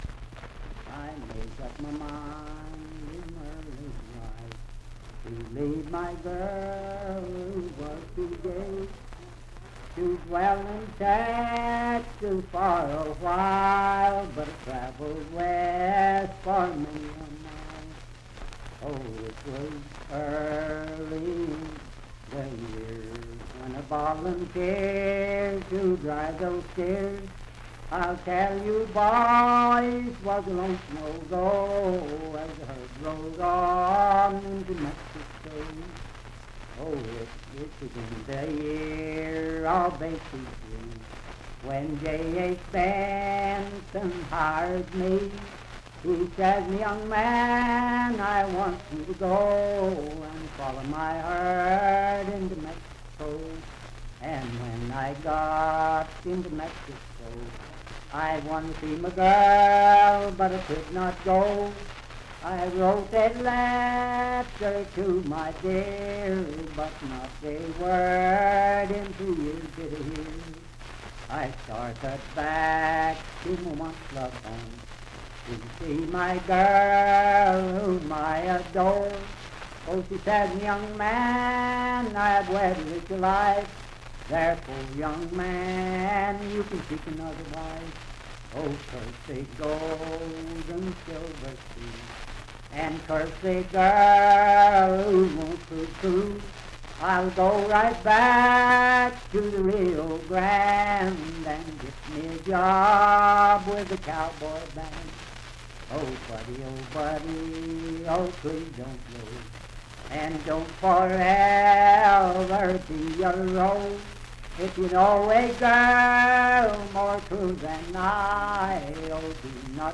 Unaccompanied vocal music
Voice (sung)
Parkersburg (W. Va.), Wood County (W. Va.)